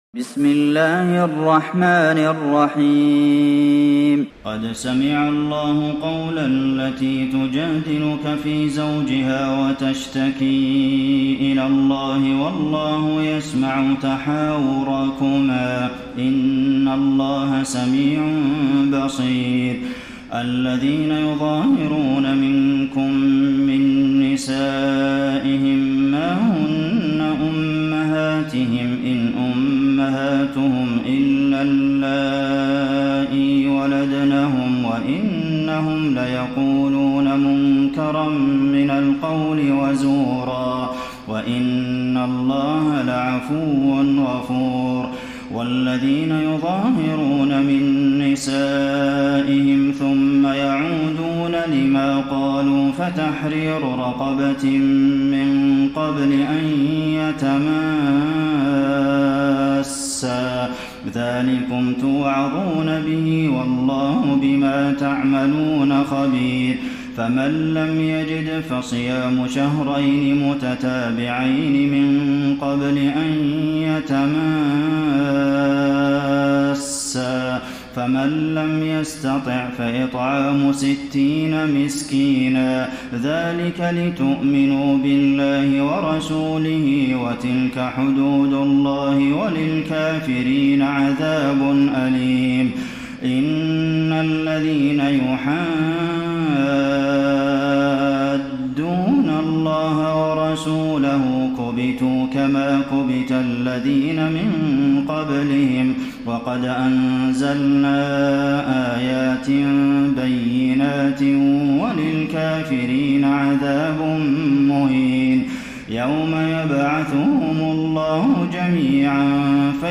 تراويح ليلة 27 رمضان 1433هـ من سورة المجادلة الى الصف Taraweeh 27 st night Ramadan 1433H from Surah Al-Mujaadila to As-Saff > تراويح الحرم النبوي عام 1433 🕌 > التراويح - تلاوات الحرمين